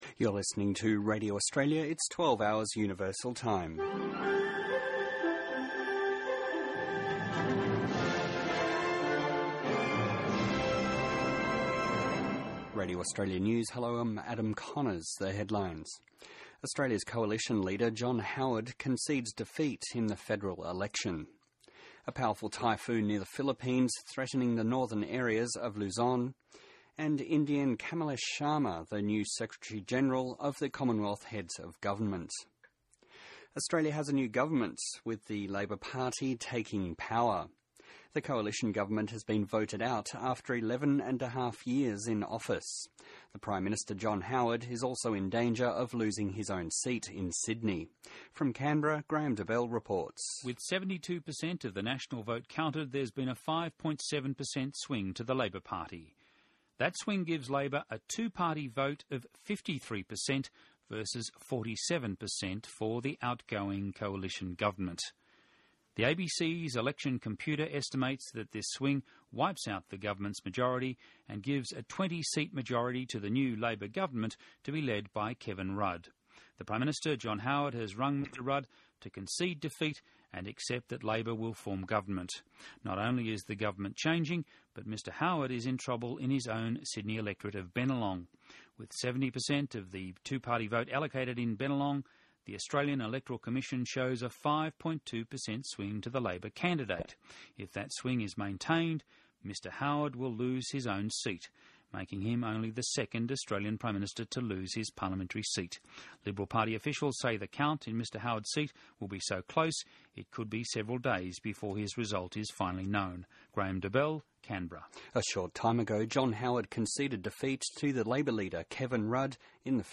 News Bulletin Australian Election 2007
This is the 11pm bulletin during federal election night 2007 from the Melbourne studios of Radio Australia.